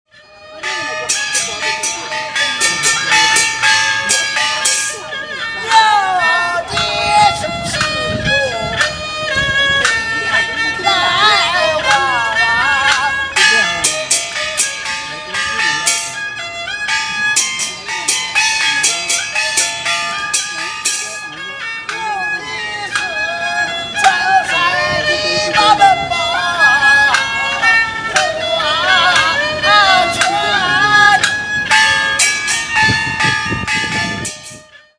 If you’re looking for “extreme singing,” you’ll find Chinese opera at a few nearby parks (click here to listen to
OperaMusic.mp3